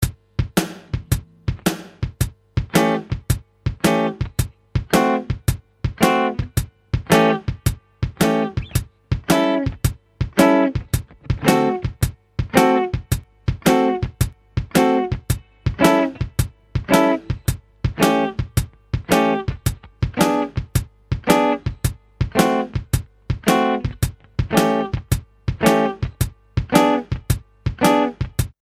You can strip the rhythm back to just strumming short chords on beats 2 & 4 of the bar which keep in time with the snare drum.
In general the chords work well with just the lighter 1-4 strings being used.
2 & 4 Rhythm | Download
blues_motown.mp3